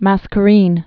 (măskə-rēn)